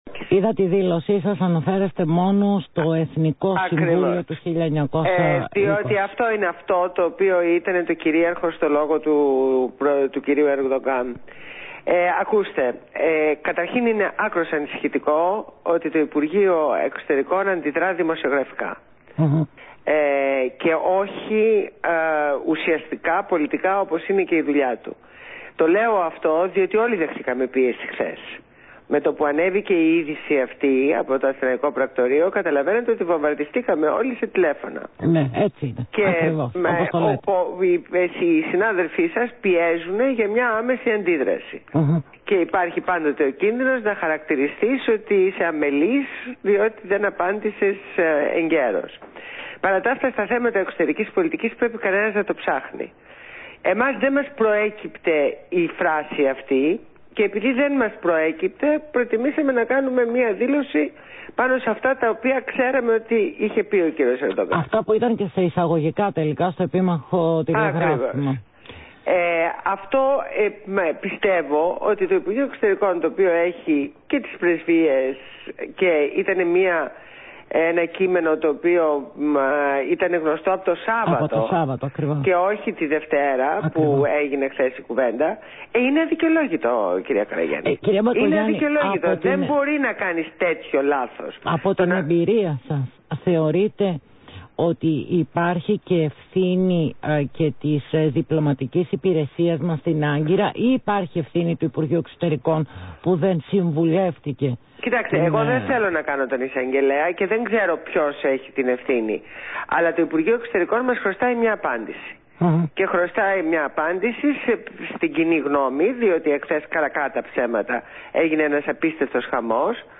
Συνέντευξη στο ραδιόφωνο Αθήνα 9,84